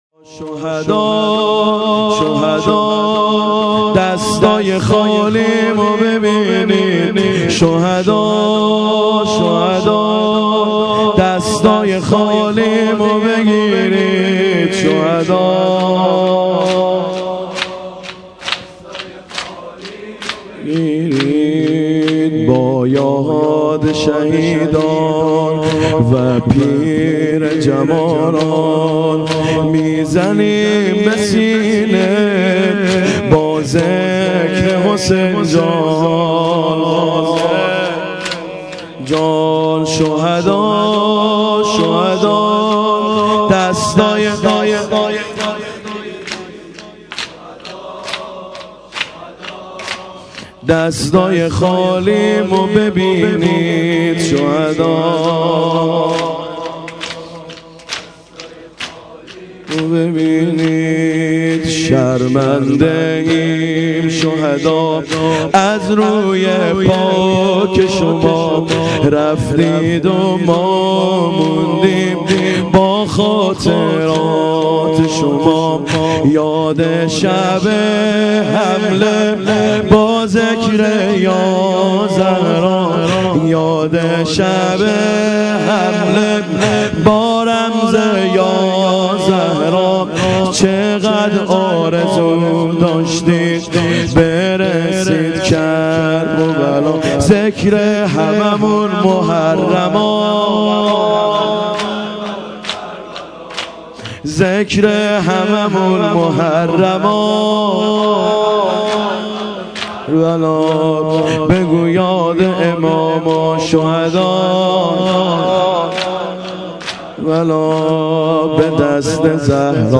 maddahi-177.mp3